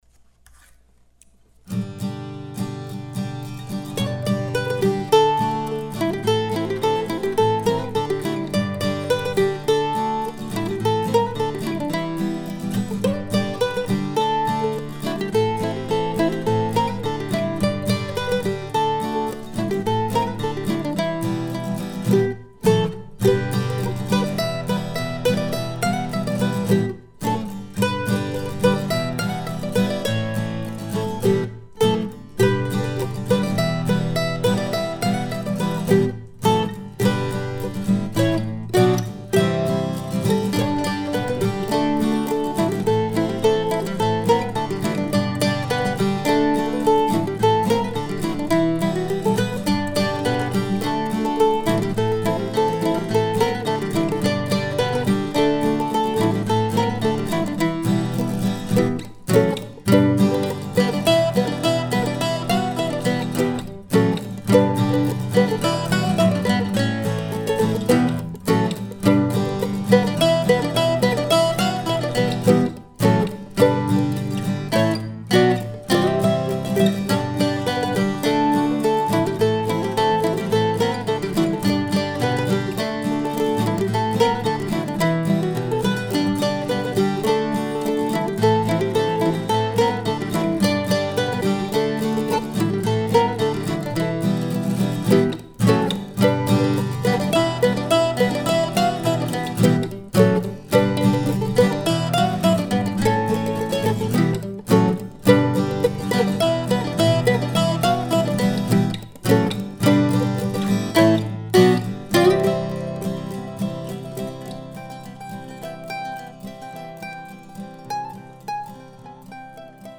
So back in April I was toying with the idea of bringing a mandolin to class to demonstrate the quote and I ended up writing this dance tune instead.
The recording is at a slower tempo than you might want to use for contra dancing. I also got out my old Flatiron octave mandolin to double the melody and add a little harmony in the B section.